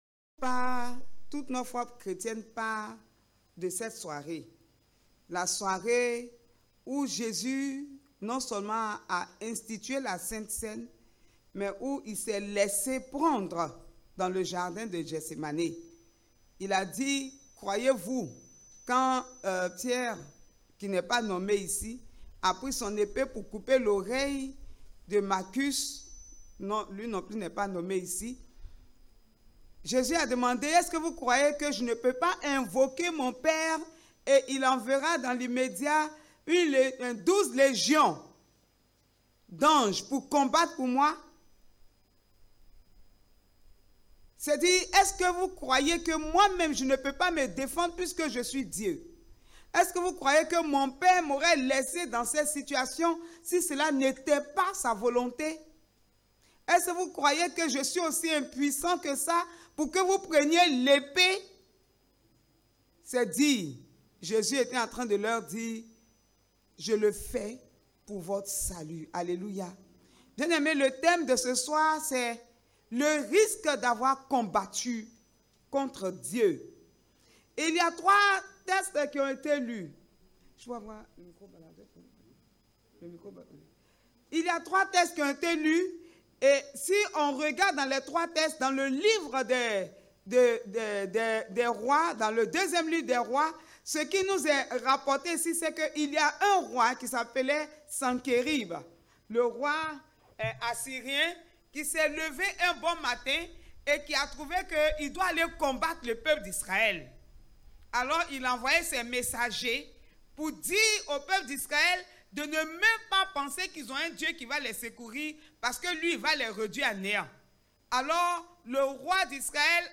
Sermon du mini-culte à l’occasion du Jeudi Saint 2021 à Sinaï de la Riviera Béssikoi.